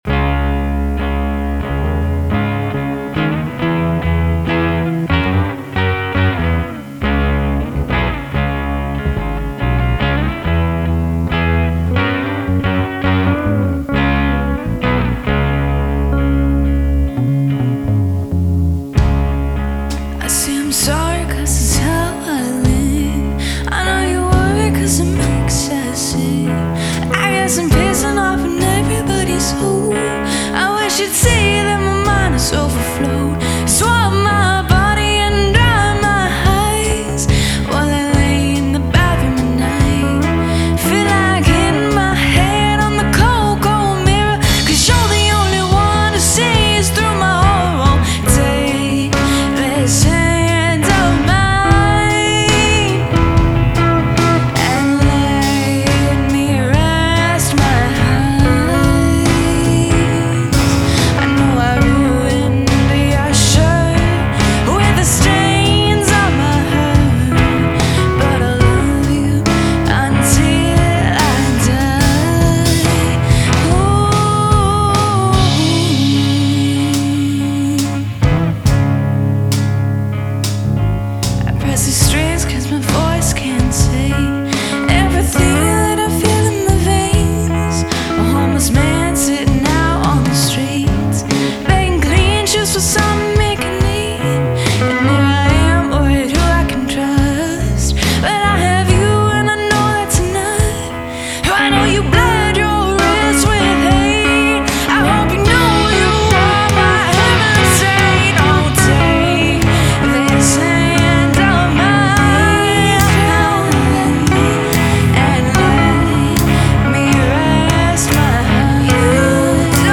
I added bass, some drums and the ending guitar to this tune